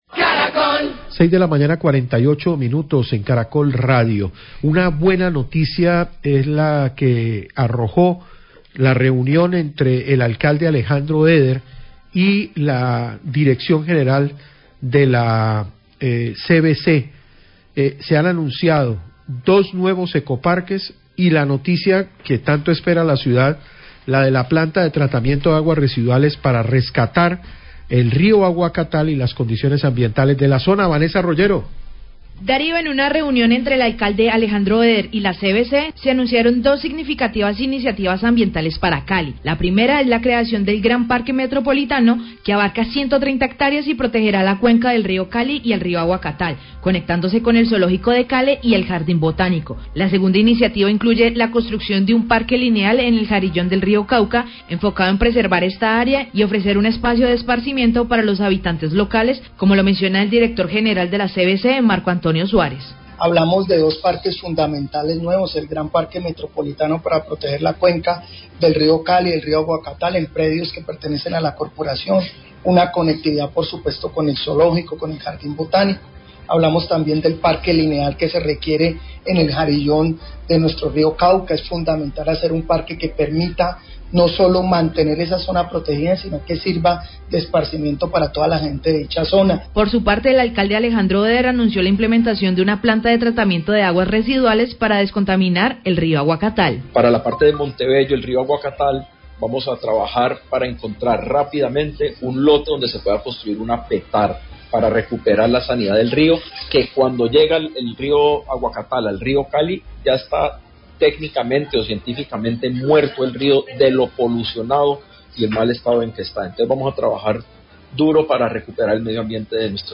Declaraciones del director general de la CVC, Marco Antonio Suárez, y del alcalde Alejandro Eder, tras la reunión donde se acordó la creación del Gran Parque Metropolitano que protegerá la cuenca del río Cali, un parque lineal para el jarillón del río Cauca y definir dónde se construirá PTAR del río Aguacatal.